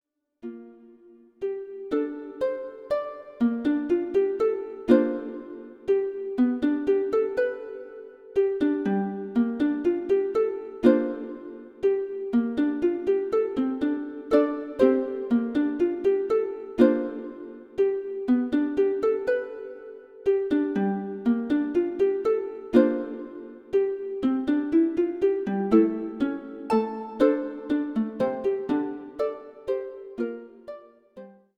サブの伴奏にハープを使用します。
メイン伴奏がピアノですが、その音を邪魔しないよう、音の隙間を縫って音を入れていきます。
また、今回のピアノは左に低音、右に高音が振られているので、ハープは左奥に配置しています。
luflen-harp-5.mp3